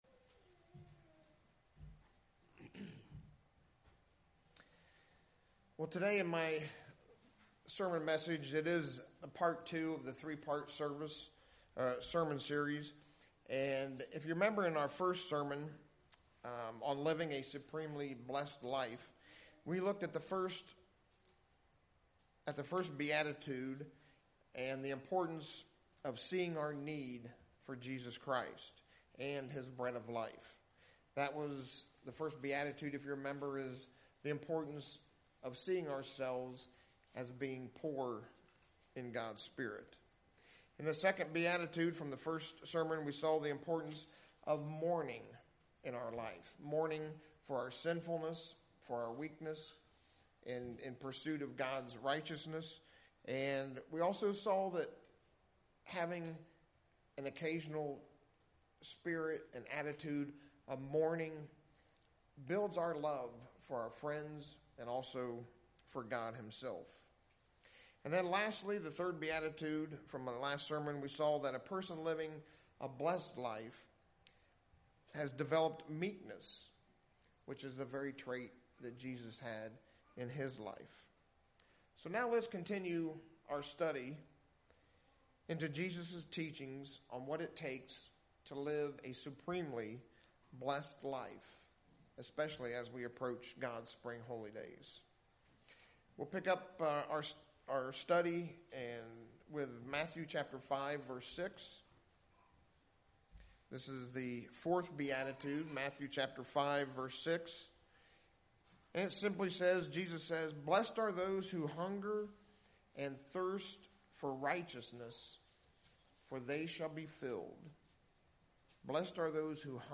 Sermon
Given in Lehigh Valley, PA